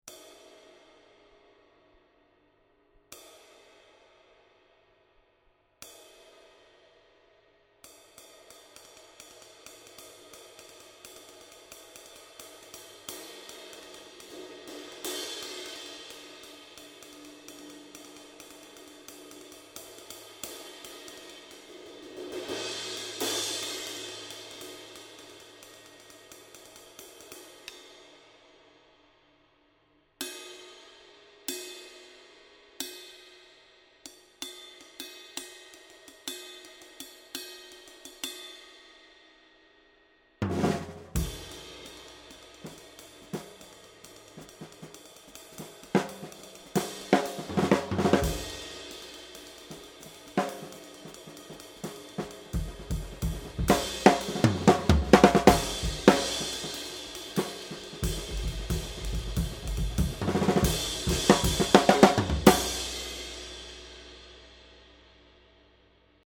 22" Master Vintage Ride (Ex-dry) 2412g